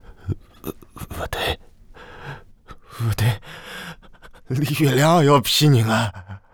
序章与第一章配音资产
c02_3疯子_2.wav